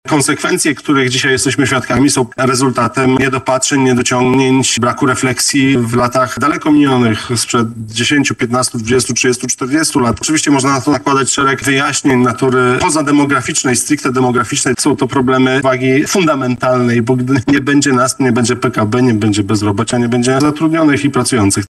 • mówi Prezes Głównego Urzędu statystycznego Dominik Rozkrut.